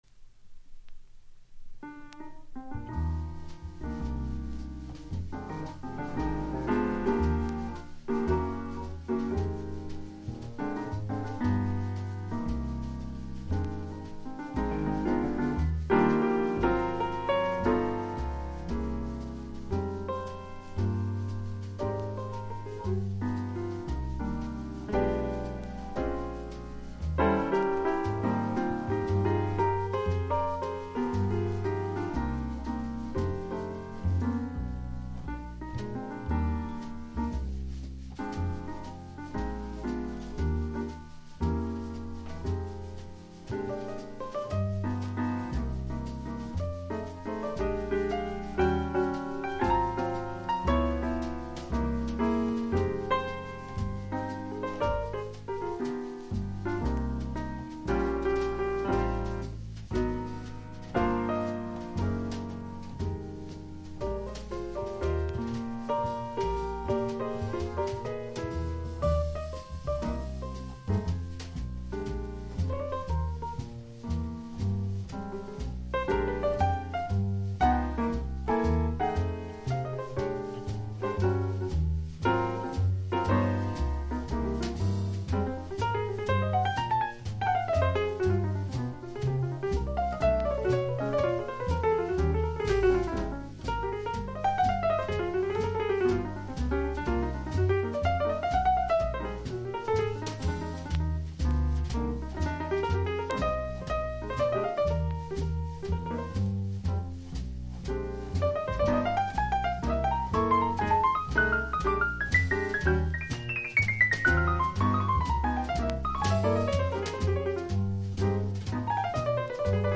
トリオもの名盤